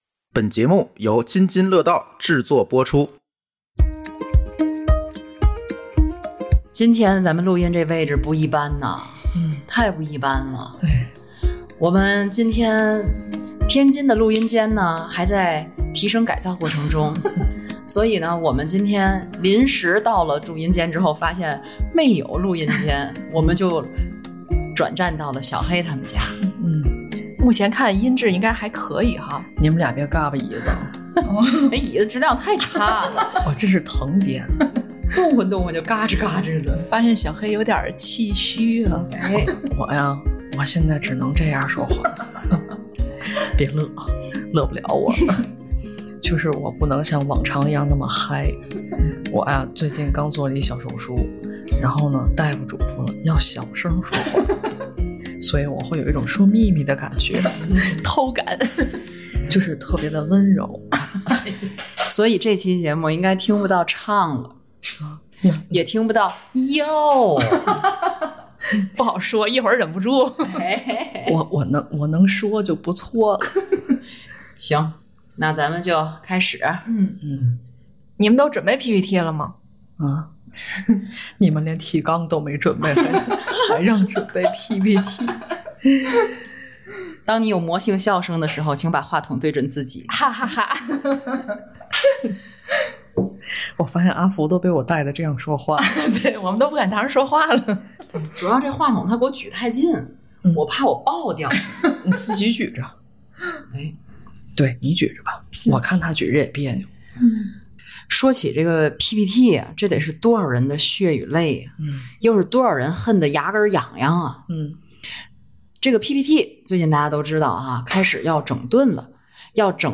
《记者下班》三位主播也有着各自的ppt“血泪史” 企业里，ppt几乎无所不在。